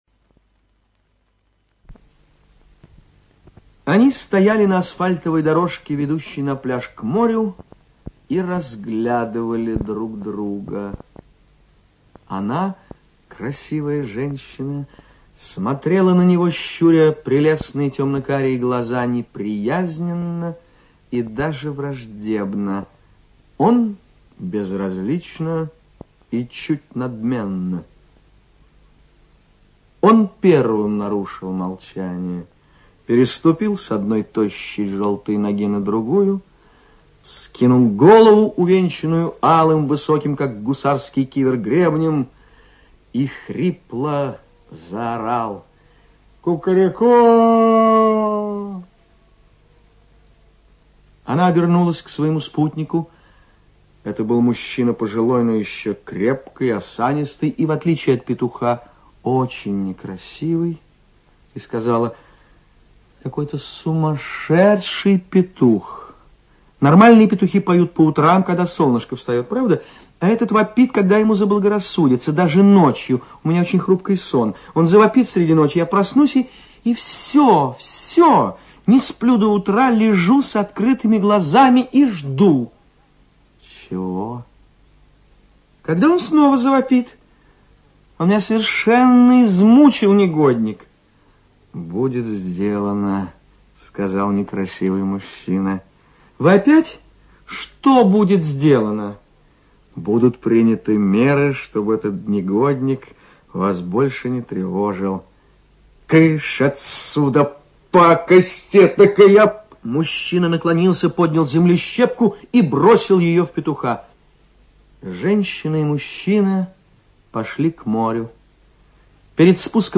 ЧитаетТабаков О.